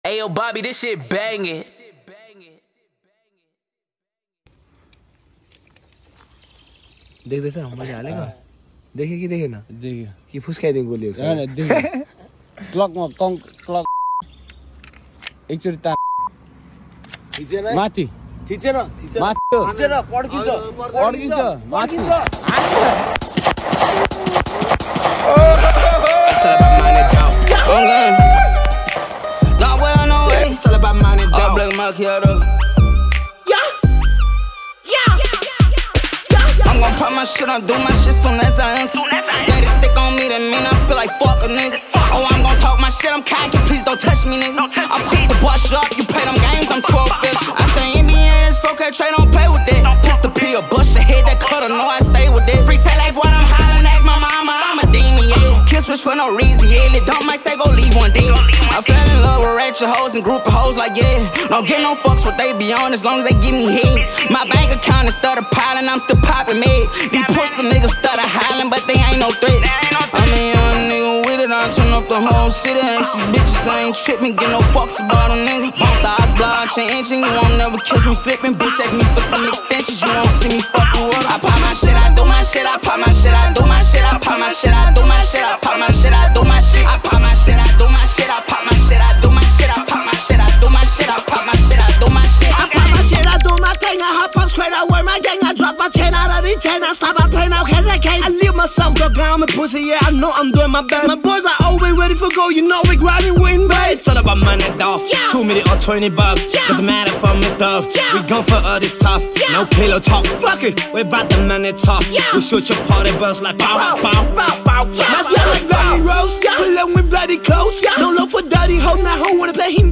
# Nepali Mp3 Rap Song